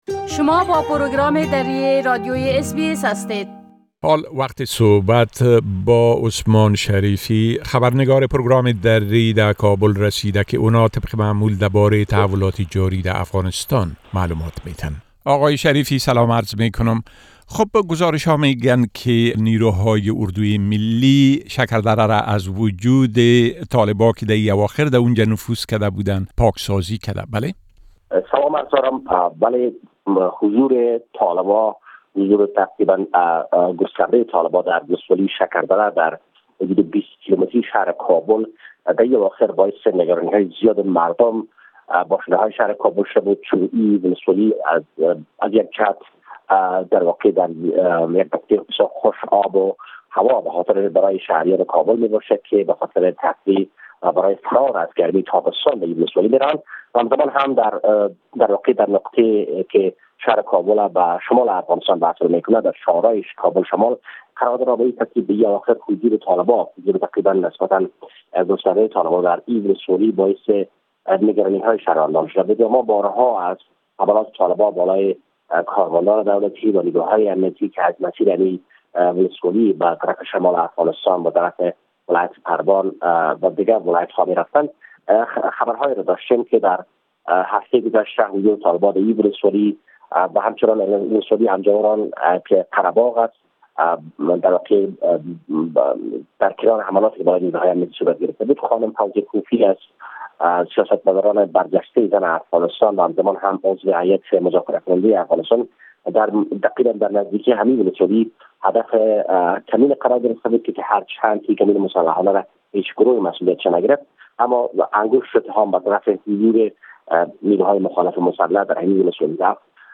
گزارش كامل خبرنگار ما در كابل بشمول اوضاع امنيتى و تحولات مهم ديگر در افغانستان را در اينجا شنيده ميتوانيد.